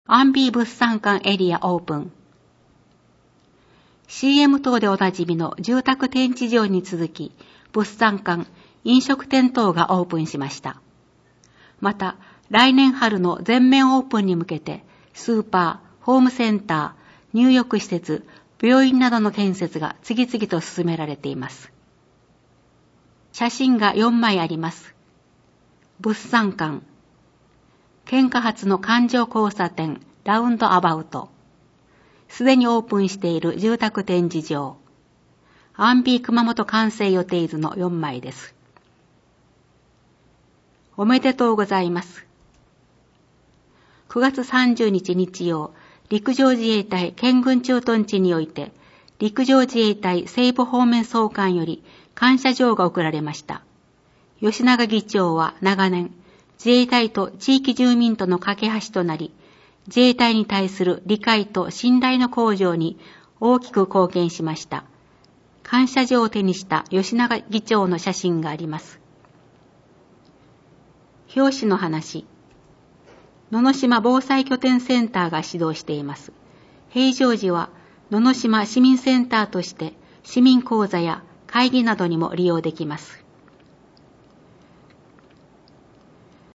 音声訳版 議会だより第５１号